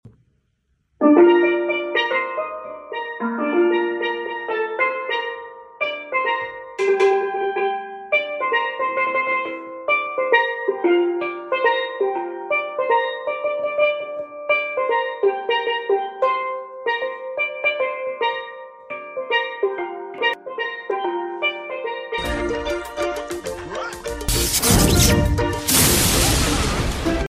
Steel Pan